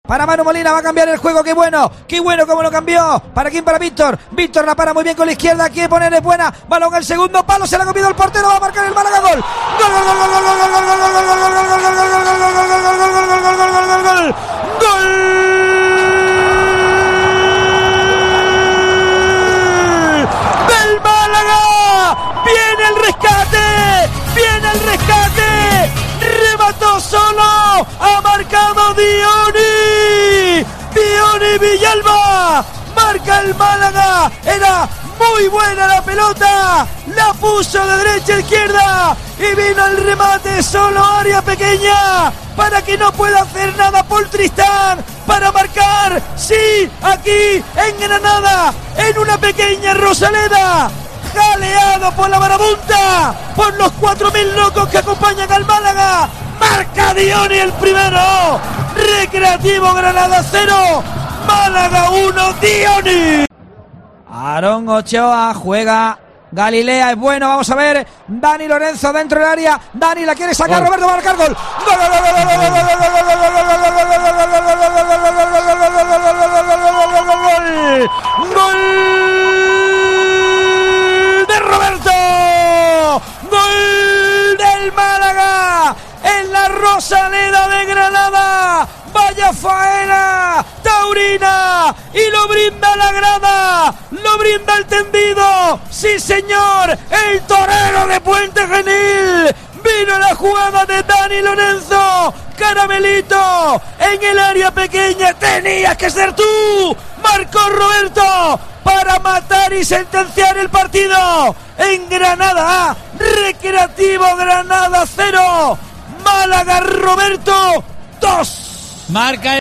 Así te hemos narrado los goles del Málaga en la victoria ante el Recreativo Granada (0-2)